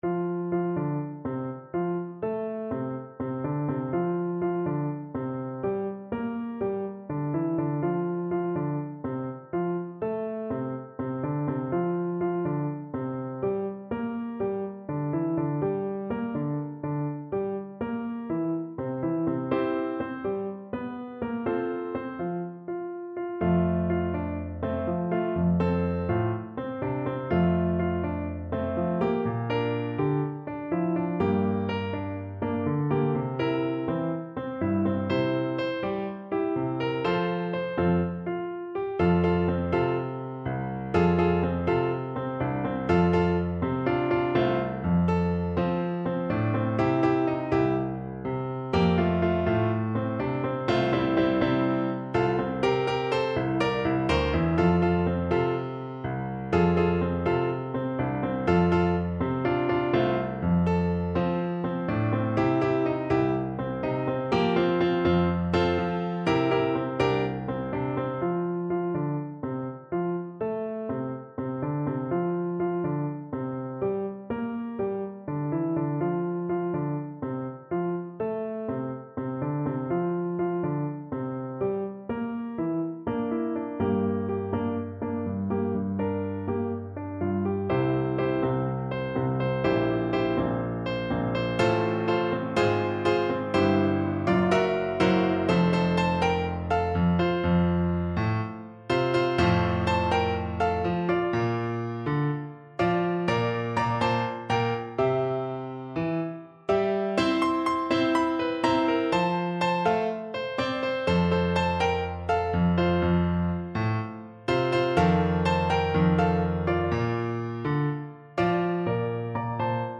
FlutePiano
4/4 (View more 4/4 Music)
Molto Allegro =160 (View more music marked Allegro)
Christmas (View more Christmas Flute Music)